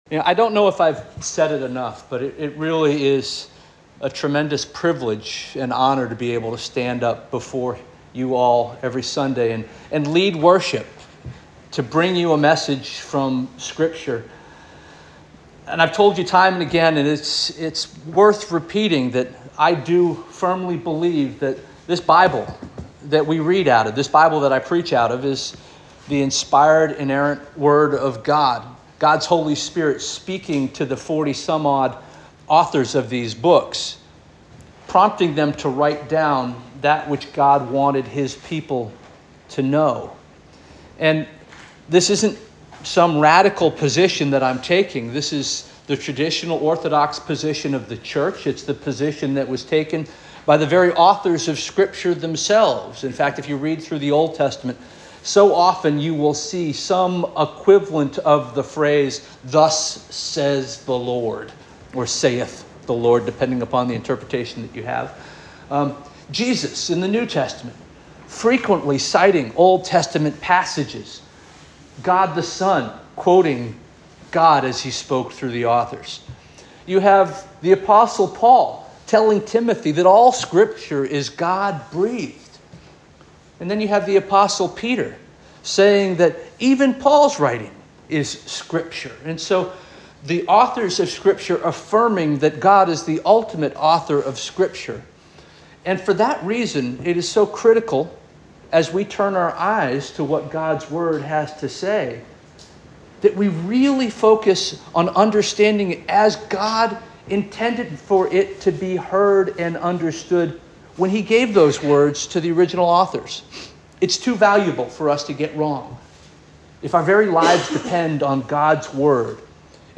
February 16 2025 Sermon - First Union African Baptist Church